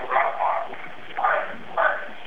recorded the sound of a barking dog.
barking.wav